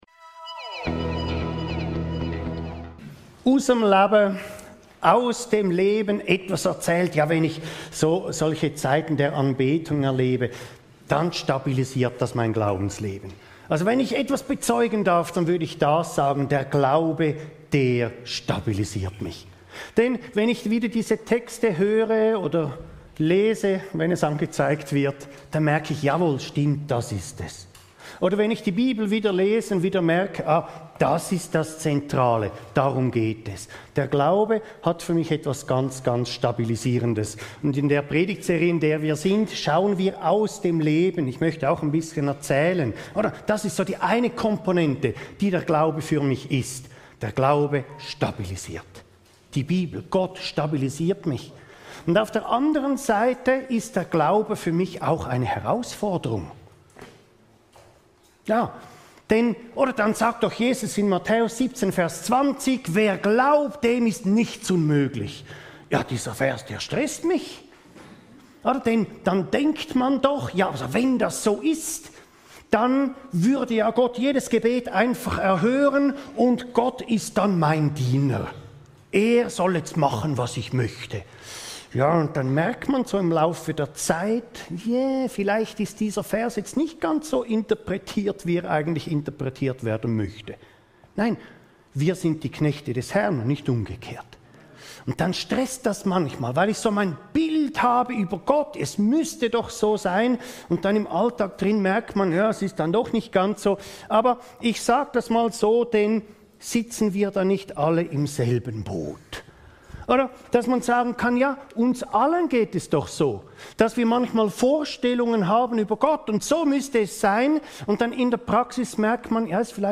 Gottes Wort trägt dich! – Wie zeitgemäss verkündigen? ~ Your Weekly Bible Study (Predigten) Podcast